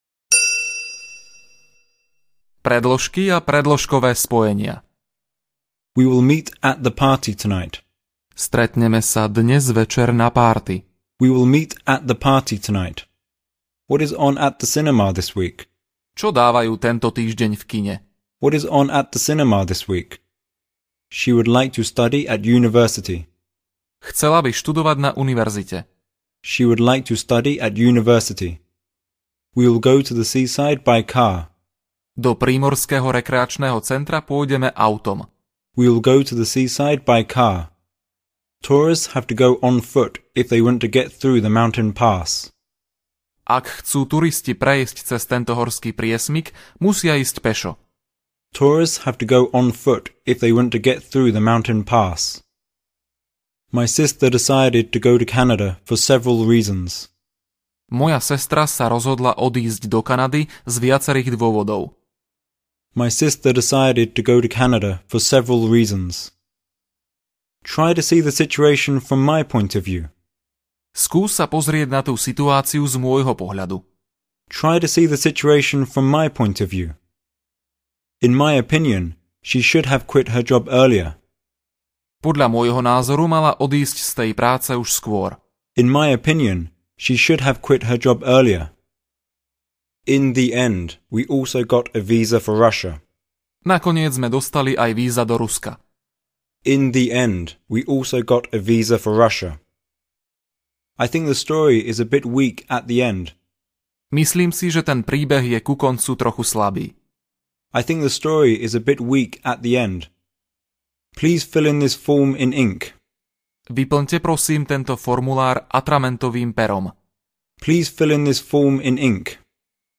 Audiokniha - Angličtina pre všetkých je určená pre samoukov. Každú vetu počujete najprv po anglicky, potom v slovenskom preklade a znovu v originálnom…
Ukázka z knihy